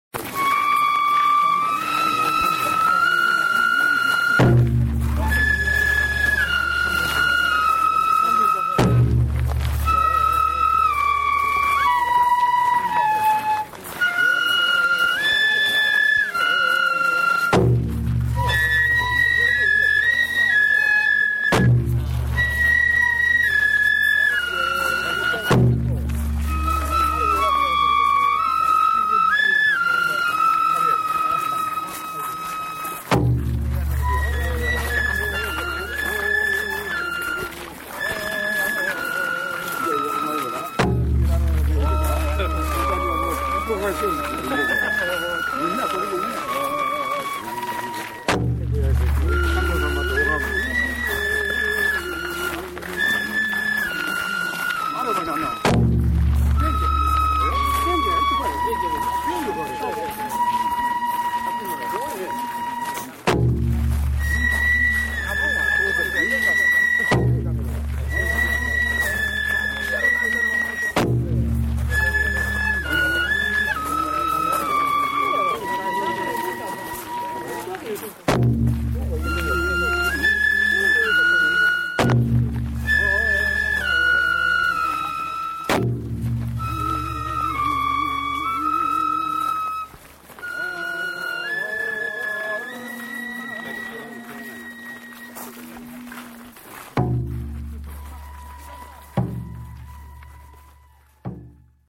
夕暮れ、熊野本宮大社の旧社地、大斎原（おおゆのはら）で神事が行われるという。
大鳥居の前で、待ちくたびれてぐったりしていたら、かすかに太鼓の音が聴こえてきた。雑談と足音と、鼻歌のようにくちずさむ歌が近づいてくる。なんてまったりと幻想的な…。